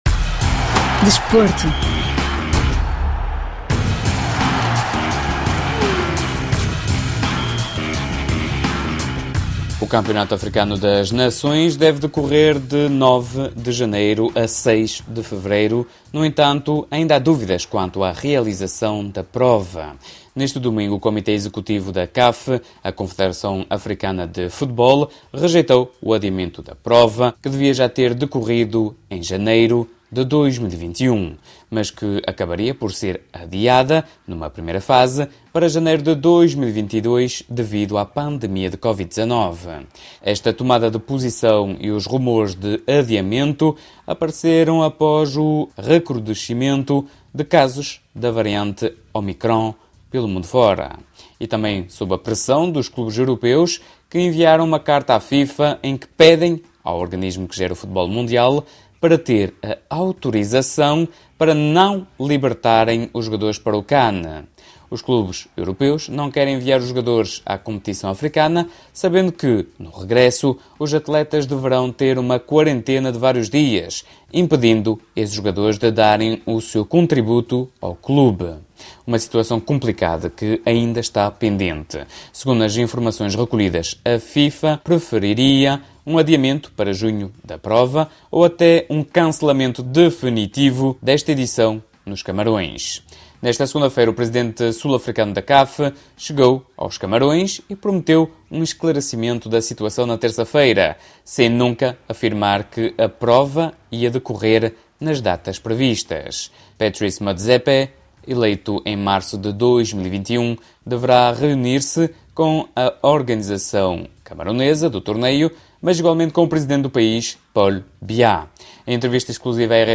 Em entrevista exclusiva à RFI